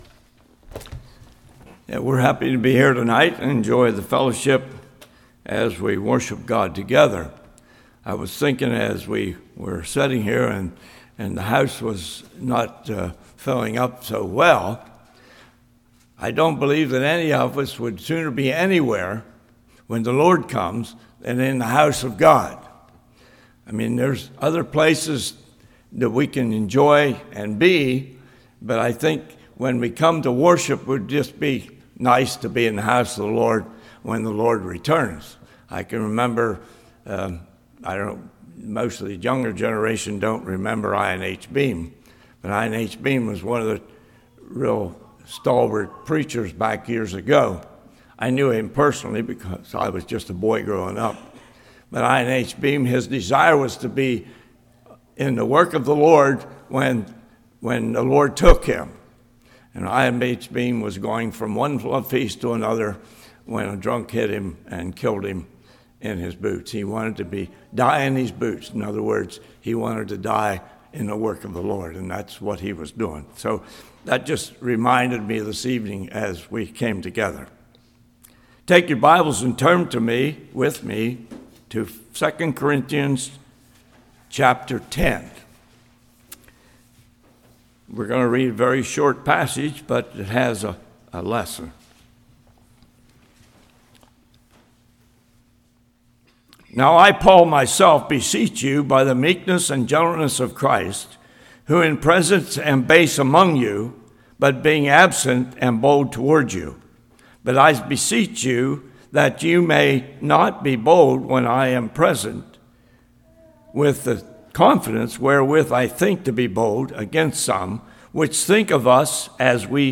2 Corinthians 10:1-5 Service Type: Evening Highlighting false concepts and using Scripture to cast them down.